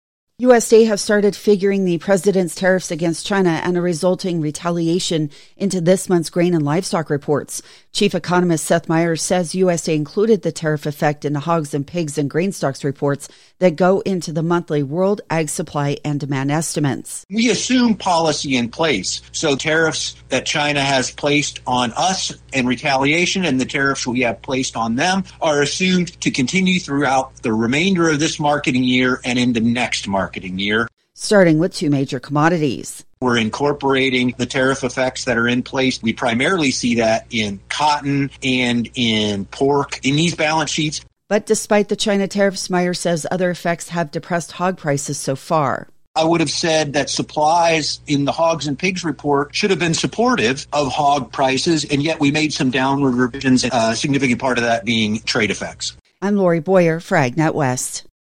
Synopsis: USDA chief economist Seth Meyers (not the funny one) discusses the impacts of tariffs on grain, livestock, cotton and processed meats. The discussion underscores the complexities of global trade policies on agriculture markets.